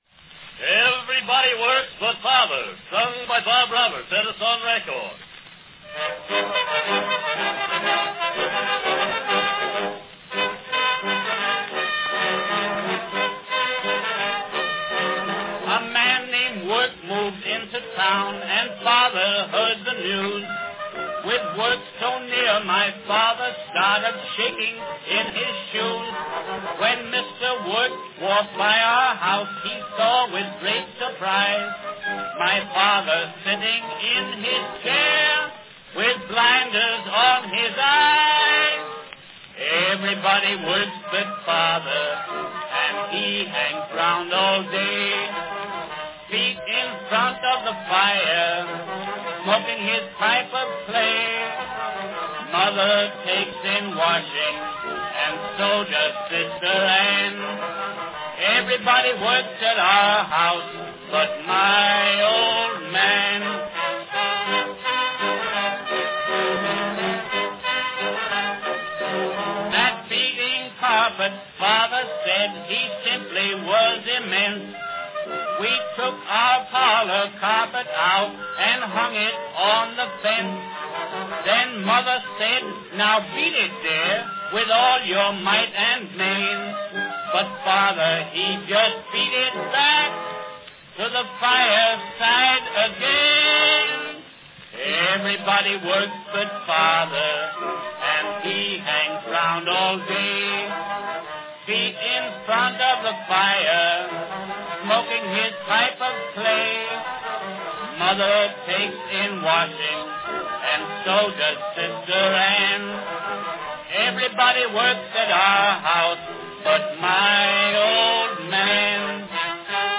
Category Song